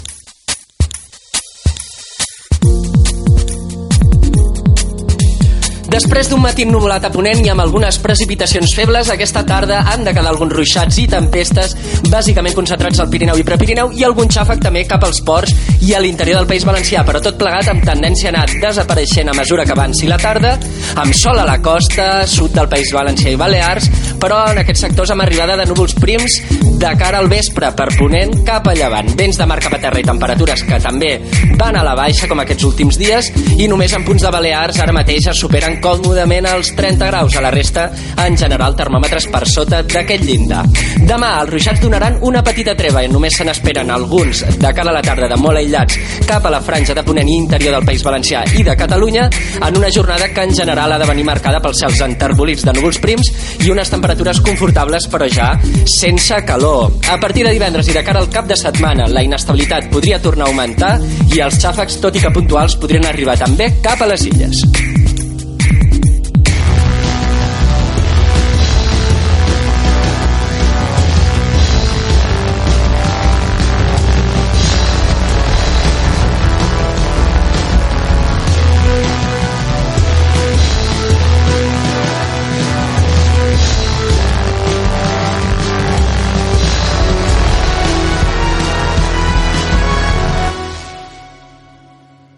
Informació meteorològica